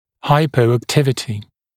[ˌhaɪpəuæk’tɪvətɪ][ˌхайпоуэк’тивэти]гипоактивность, пониженная активность